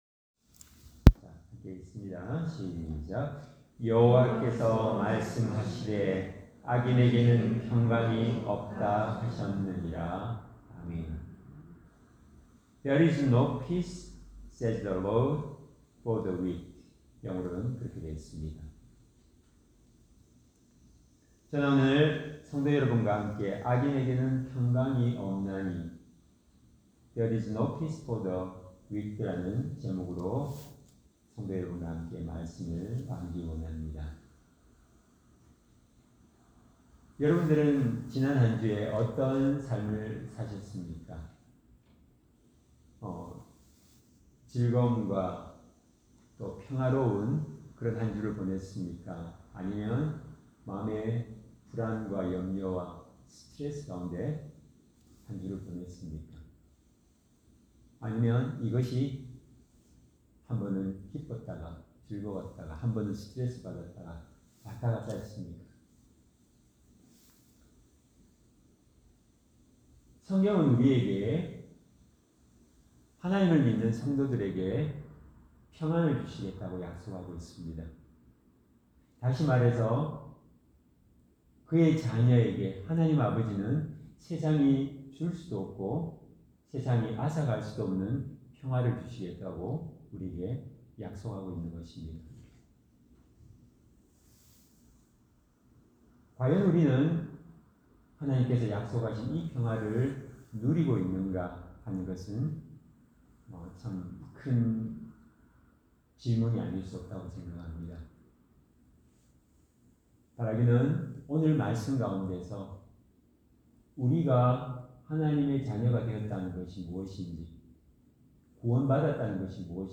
Service Type: 주일 예배